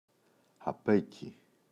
απέκει [a’peki] – ΔΠΗ